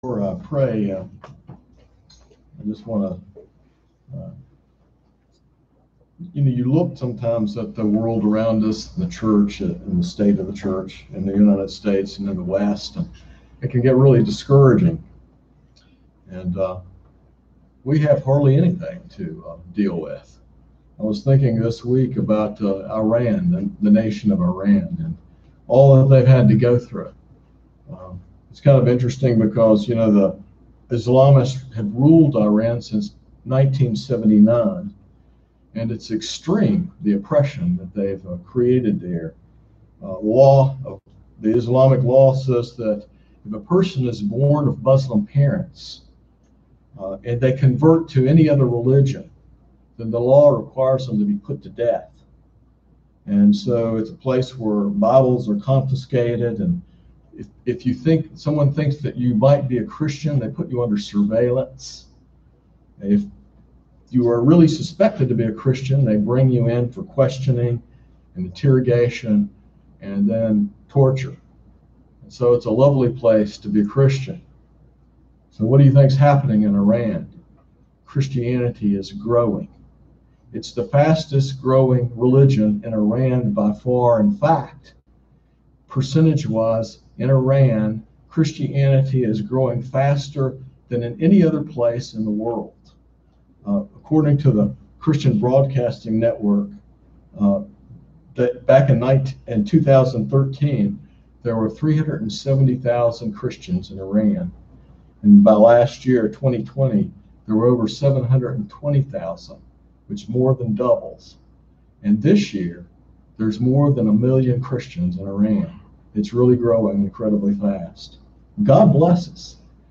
This sermon reflects on the global growth of Christianity despite persecution, the unwavering faithfulness of God, and the call for believers to honor their bodies as temples of the Holy Spirit, embracing true freedom in Christ.
sermon-7-11-21.mp3